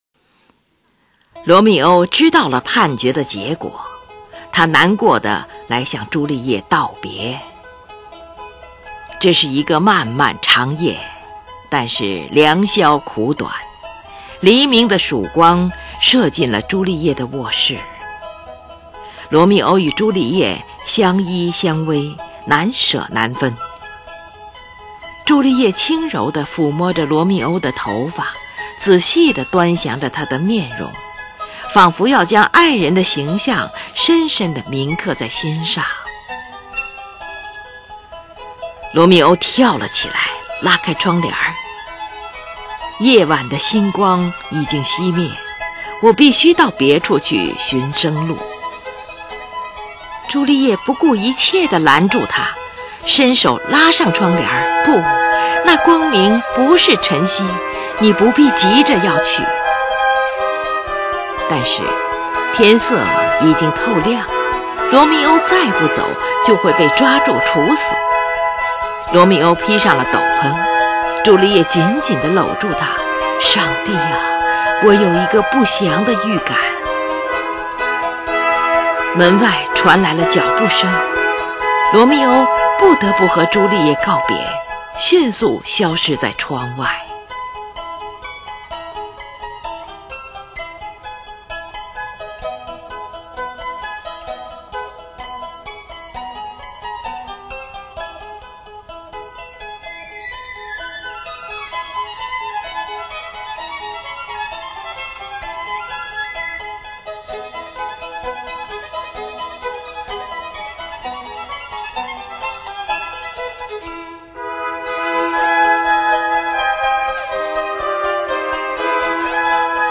美妙动人、丰富多彩的旋律与和声，复杂多样化的节奏以及雄魄壮阔、精致华丽的配器，把舞剧音乐提高到一个崭新的时代高度。